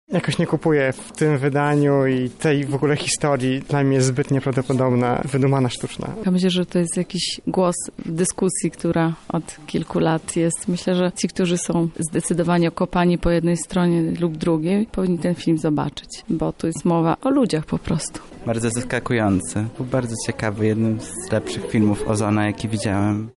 Zapytaliśmy widzów o ich opinie na temat filmu